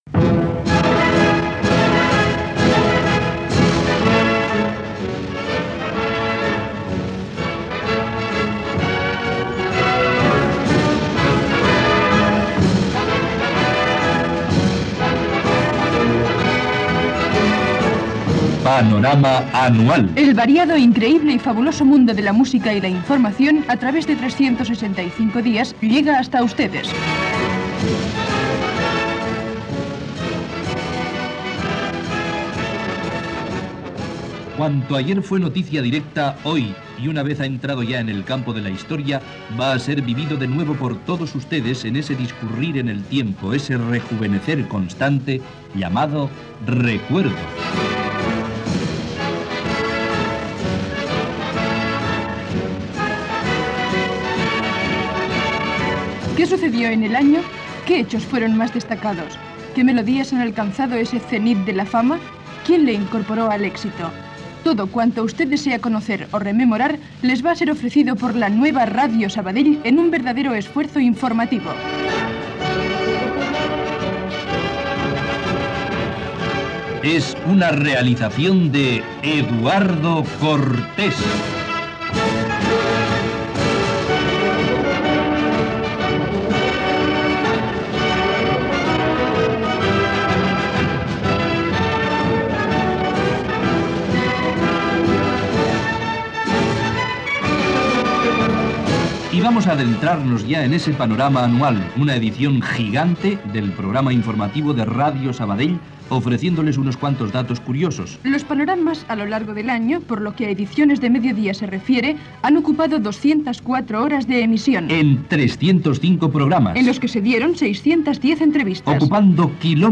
Careta del programa i presentació de resum de l'any 1970. Dades anuals relacionades amb el programa diari "Panorama".
Informatiu